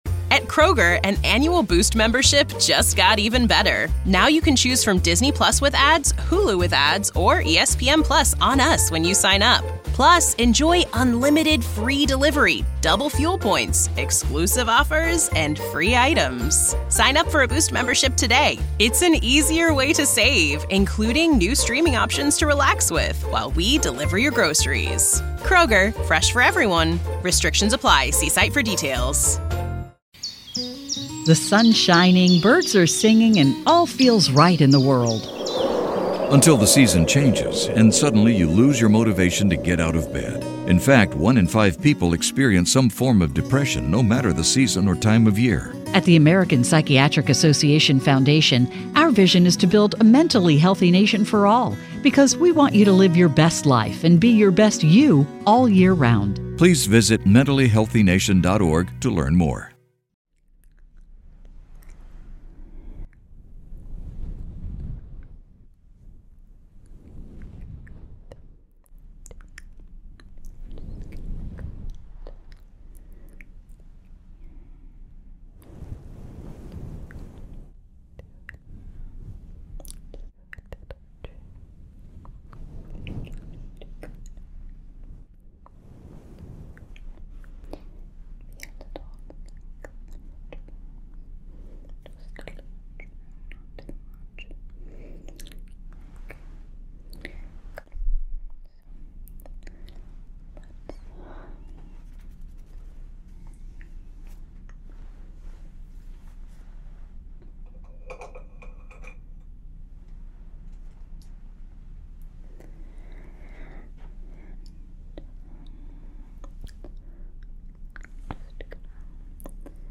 ASMR Playing With Your Hair While You Sleep